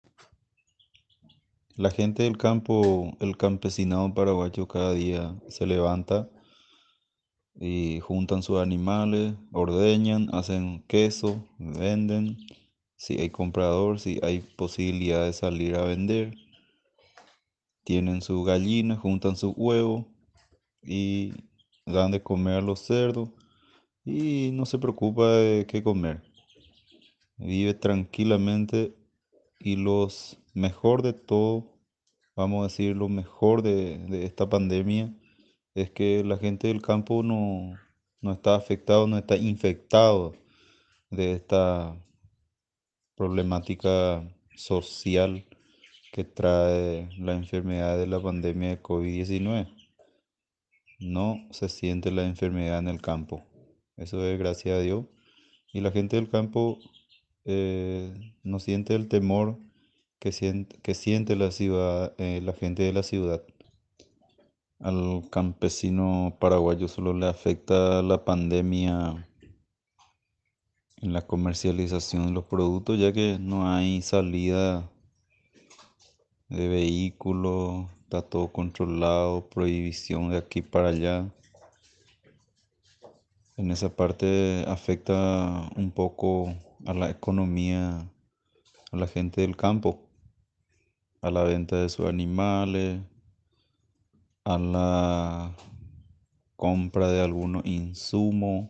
[Audio]Agricultor familiar describe de como es la vida en el campo durante la pandemia del Covid-19